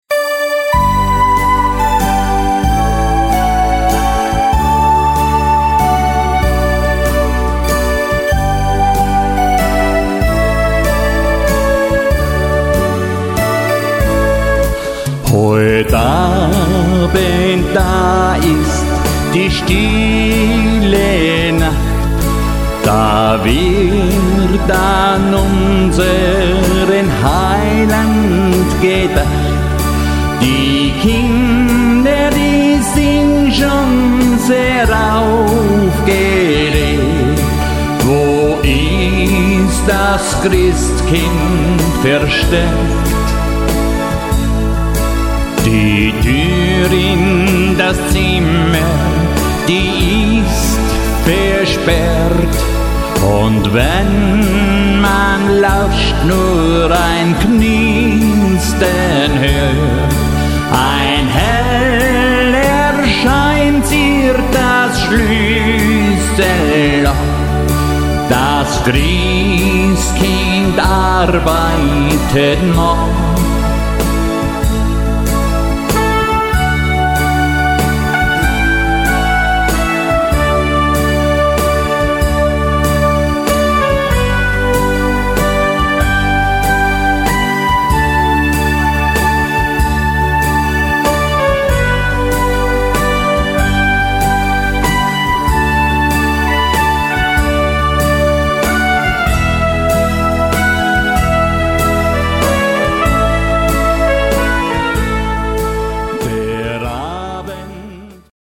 Tanz- und Unterhaltungsmusik